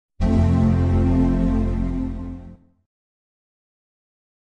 Системные звуки Apple iMac и MacBook Pro и Air в mp3 формате
1. Звук включения iMac
imac-vkliuchenie.mp3